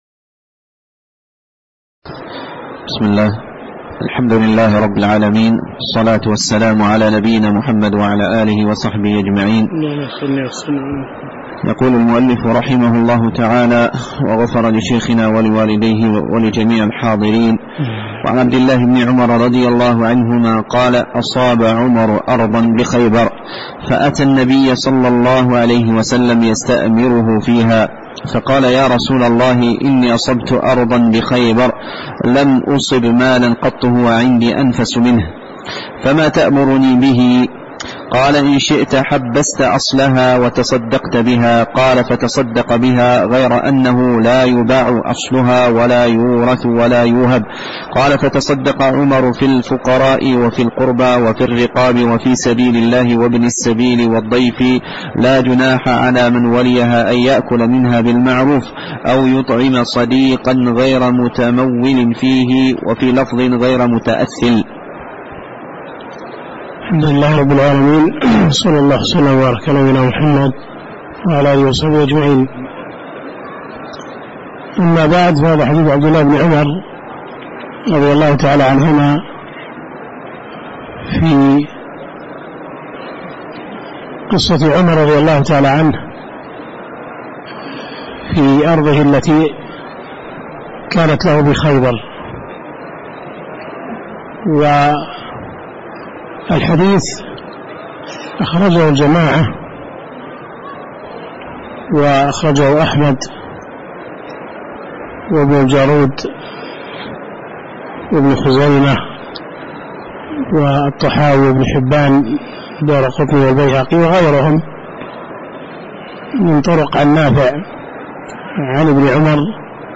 تاريخ النشر ٢٩ ربيع الأول ١٤٣٩ هـ المكان: المسجد النبوي الشيخ